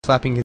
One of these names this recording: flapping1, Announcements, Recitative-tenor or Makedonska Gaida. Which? flapping1